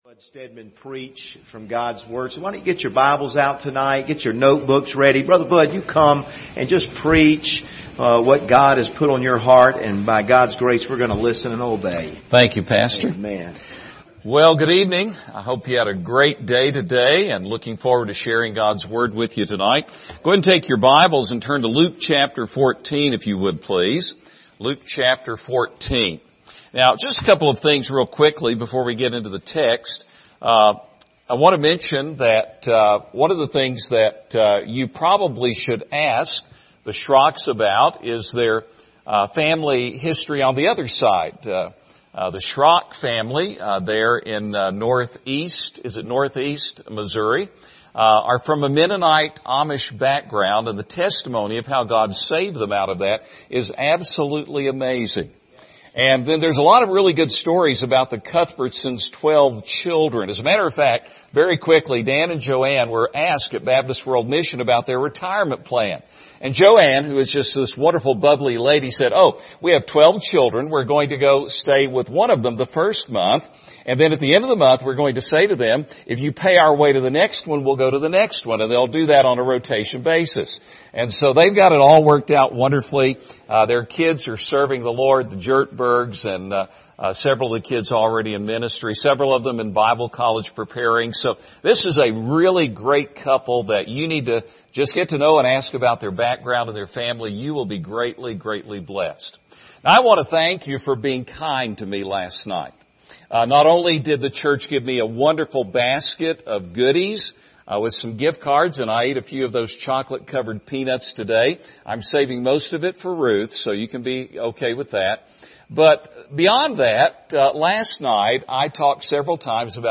Series: 2023 Missions Conference Passage: Luke 14:25-33 Service Type: Special Service